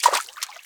WATER 1.WAV